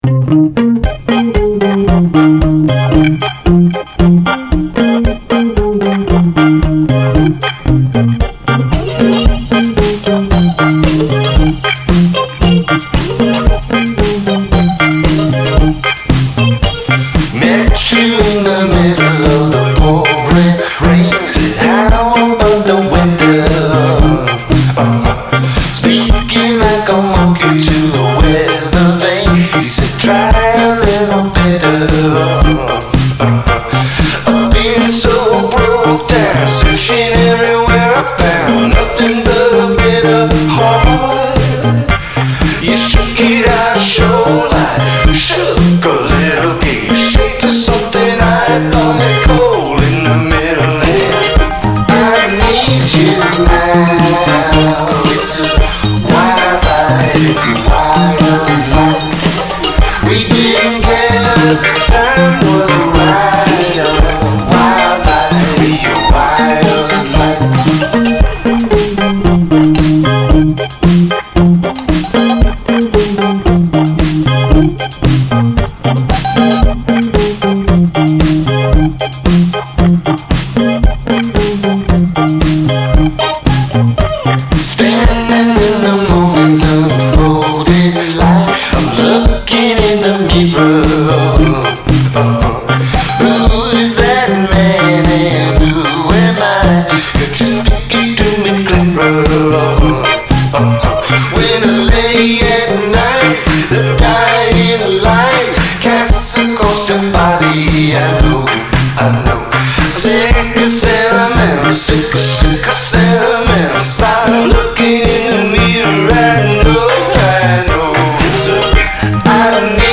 New Dance Jam